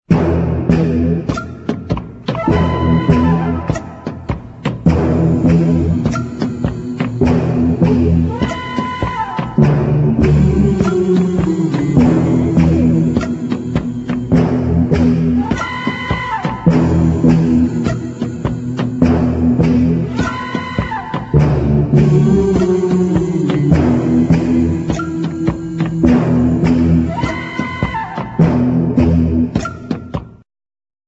1977 thrilling medium instr.